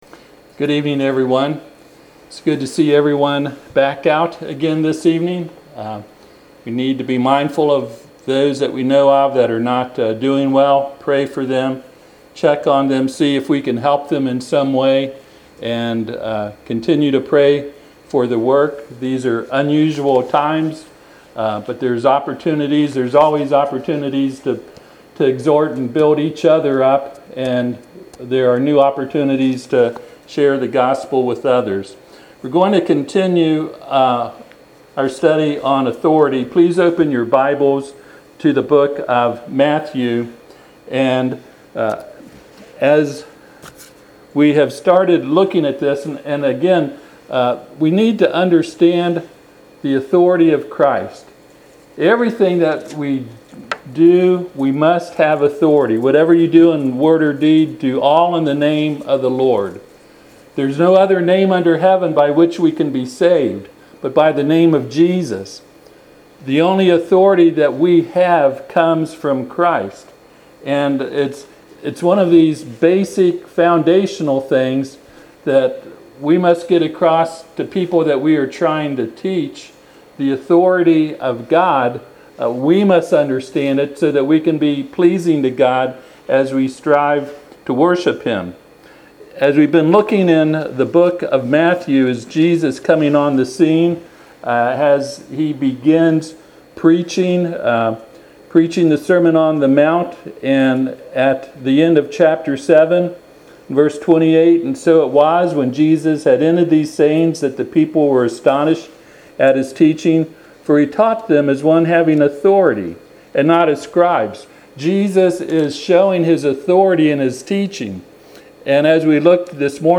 Matthew chapter 9 Miracles Preacher
Matthew 9:1-38 Service Type: Sunday PM Topics